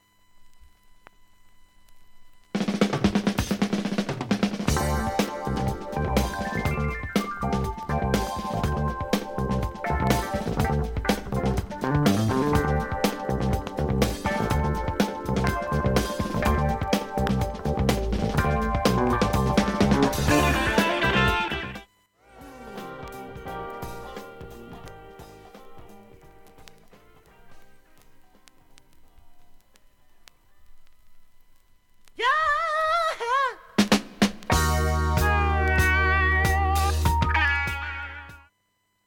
音質良好全曲試聴済み。
A-1始る前にかすかに2回プツ出ます。
A-1序盤にかすかに3回プツ出ます。
ほかかすかな単発のプツが1箇所